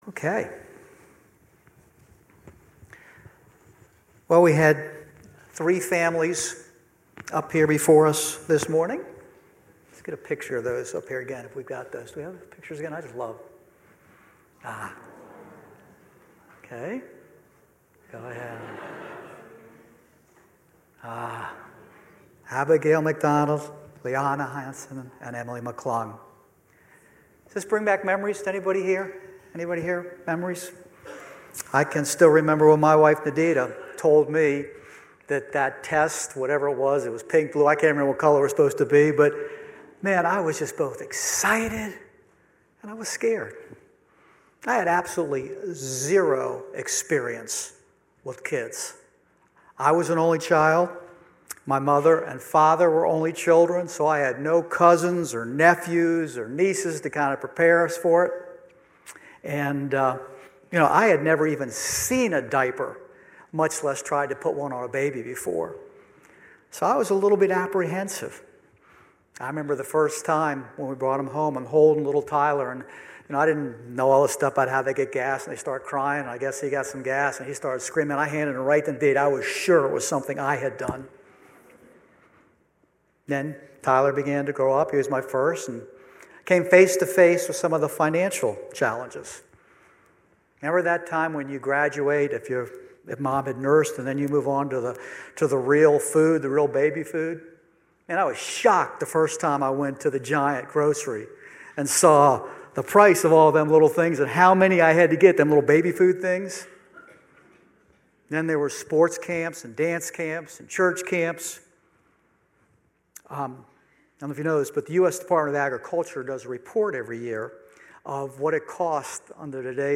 10:30 Service
Sermon